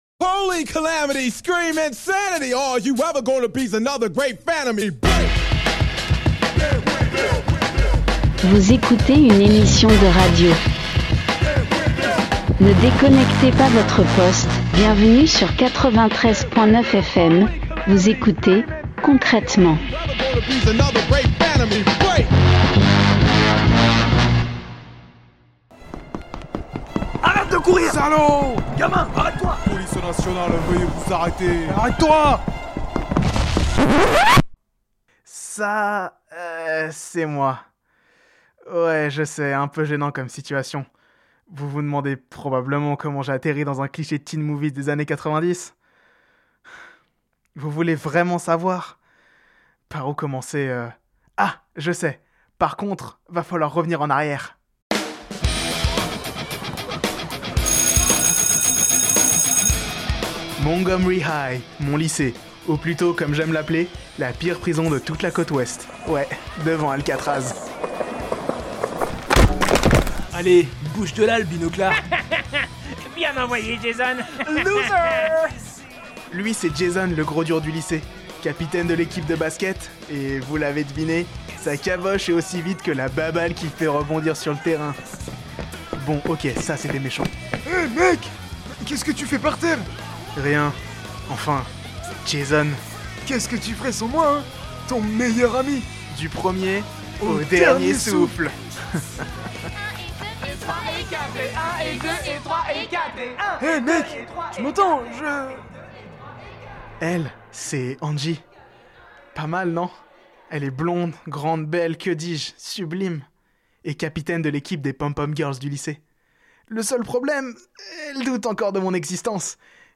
Est-il possible de produire 15 minutes de radio sur le sujet à l'aide d'un unique micro et d'une pile de chaussette servant de filtre anti-pop ?